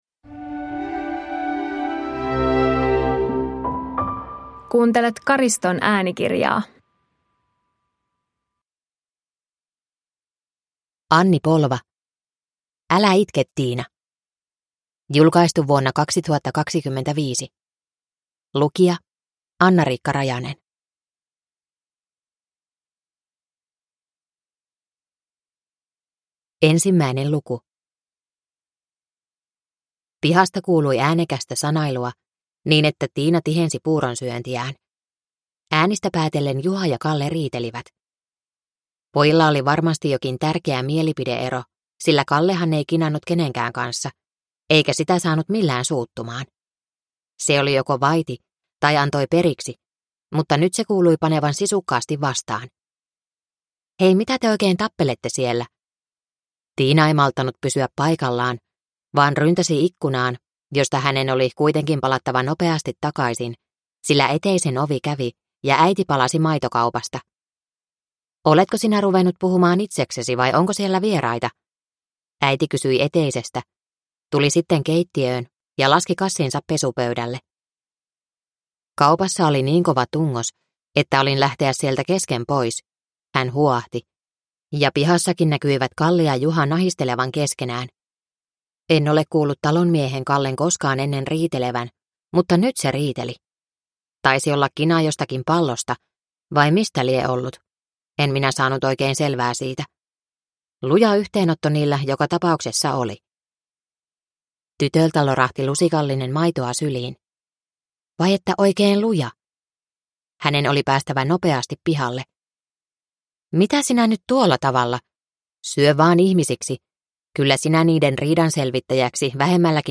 Älä itke Tiina – Ljudbok